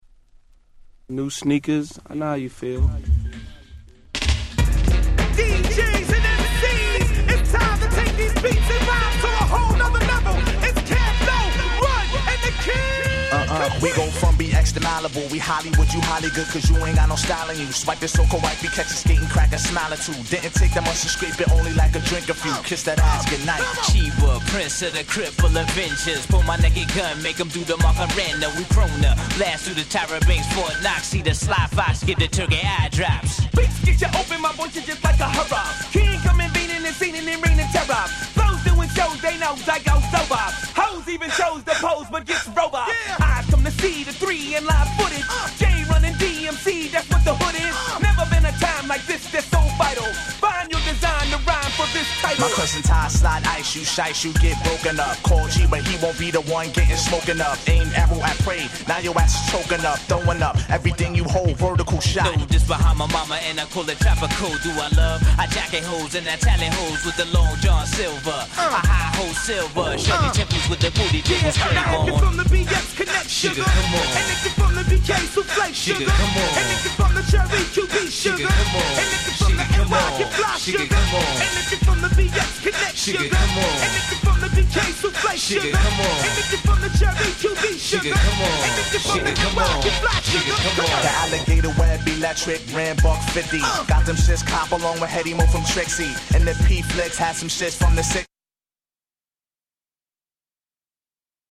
97' Smash Hit Hip Hop !!
キャンプロー 90's Boom Bap ブーンバップ